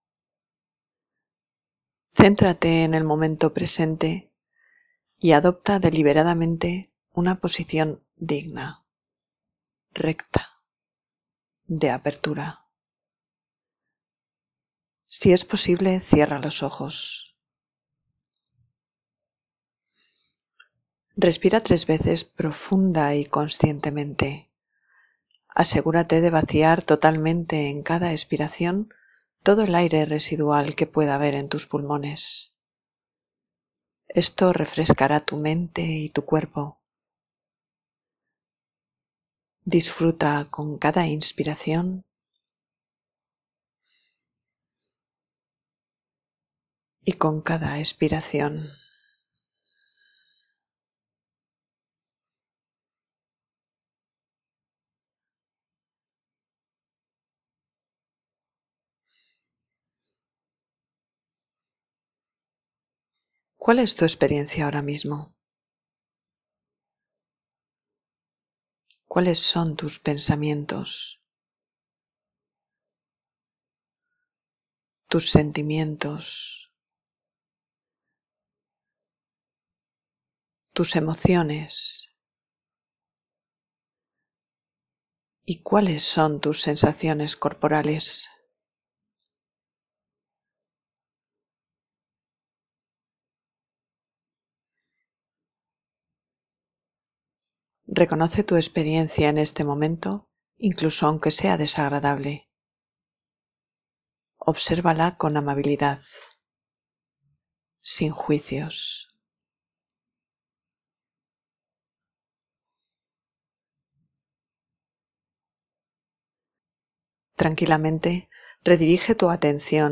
Te ofrezco una breve práctica de mindfulness para PARAR – RESPIRAR y OBSERVAR antes de ACTUAR a través de una “parada consciente